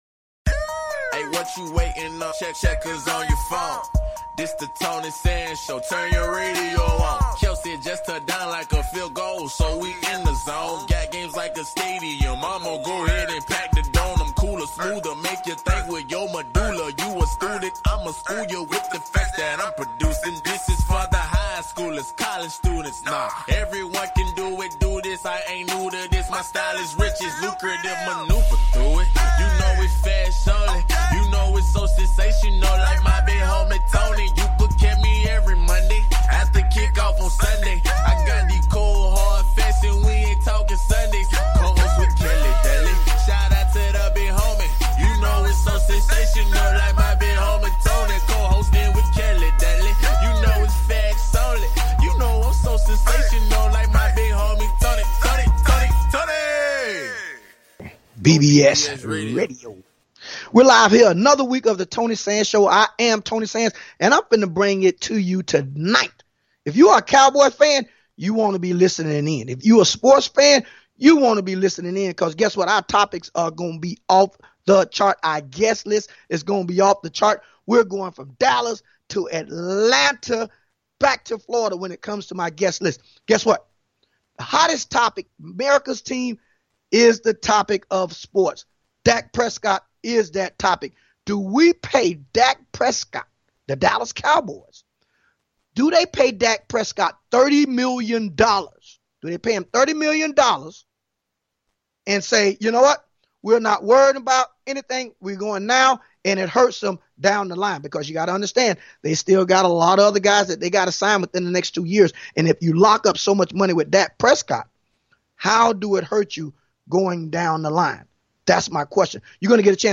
Guest, Michael Irvin, football legend
Talk Show